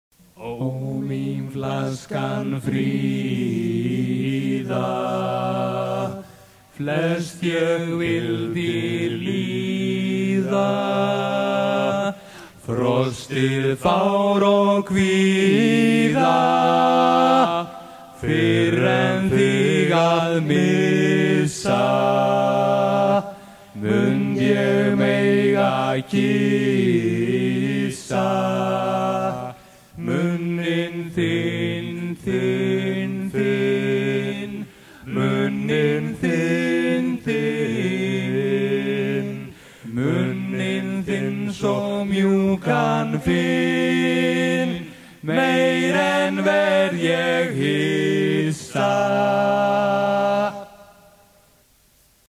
Chant Islandais
Pièce musicale éditée